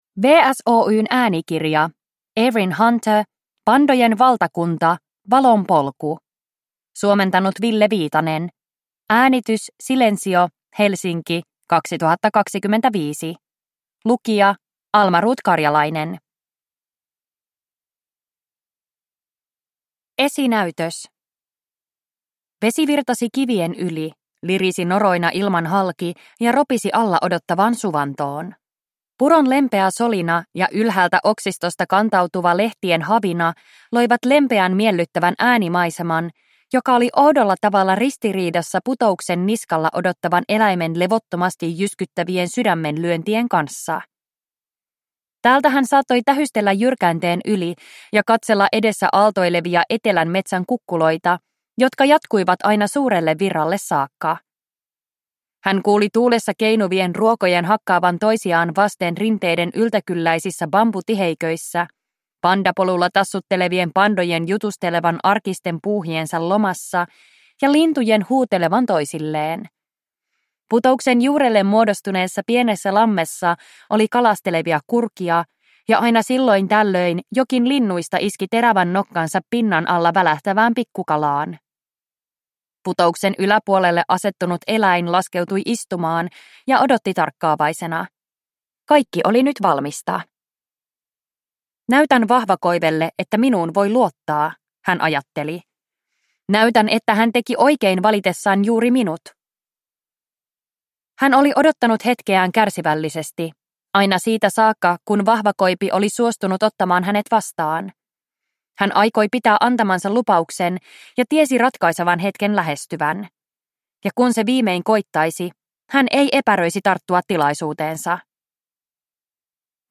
Pandojen valtakunta: Valon polku (ljudbok) av Erin Hunter